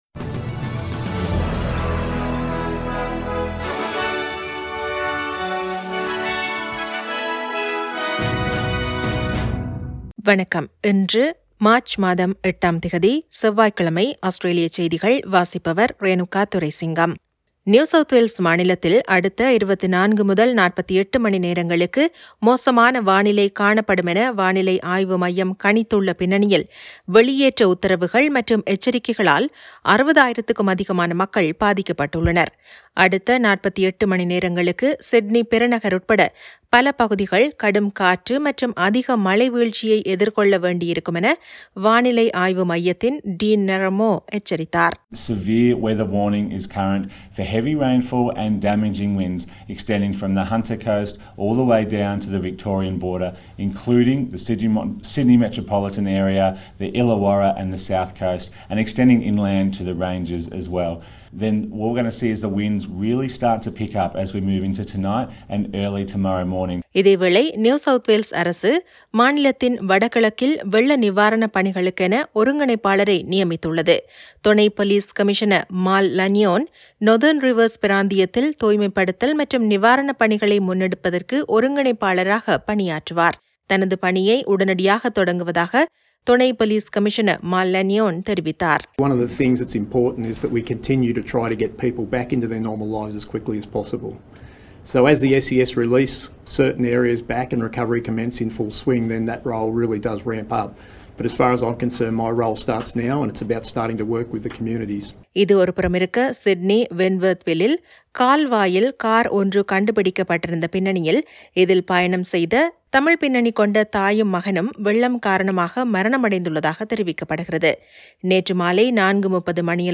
Australian news bulletin for Tuesday 08 March 2022.